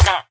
sounds / mob / villager / hit1.ogg
hit1.ogg